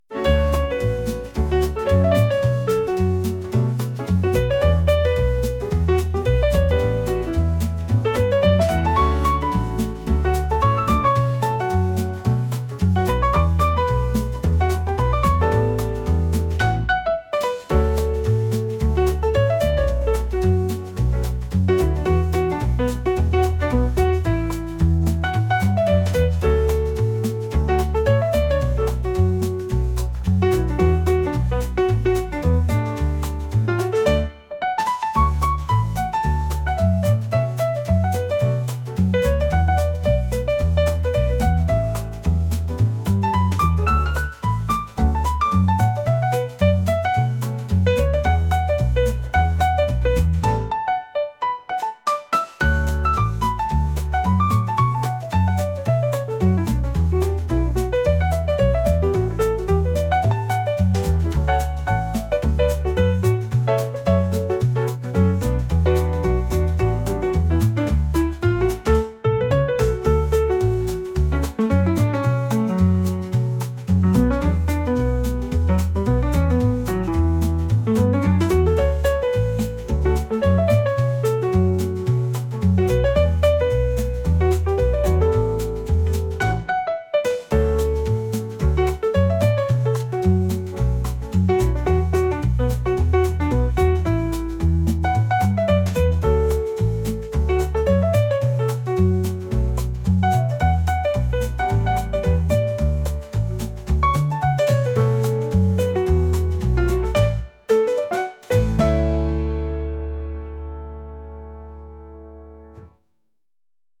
アップテンポのジャズのようなピアノの曲です。